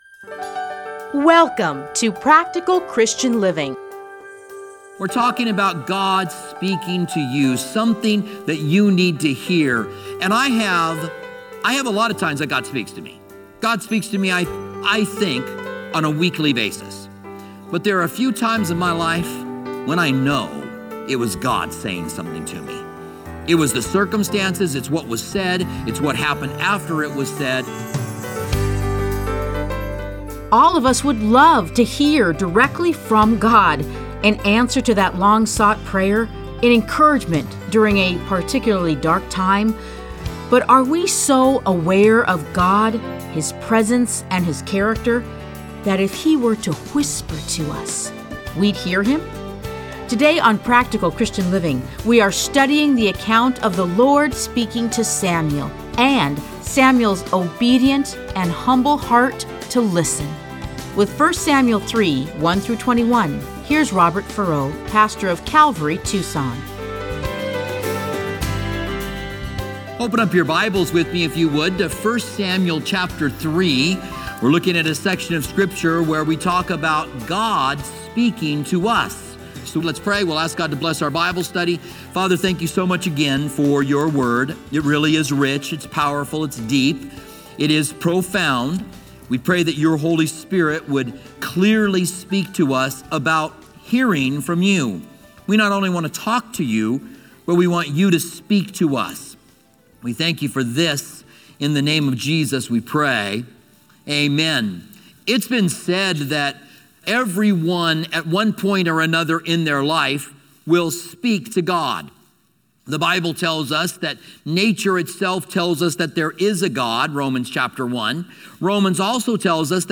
Listen to a teaching from 1 Samuel 3:1-21.